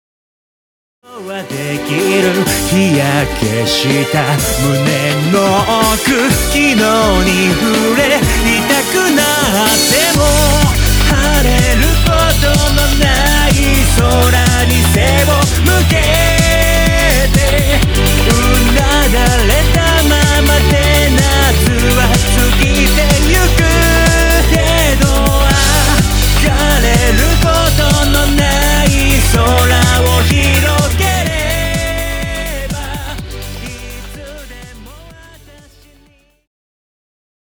独自に厳選を重ねた8曲を怒涛のロックアレンジ！
ヴォーカル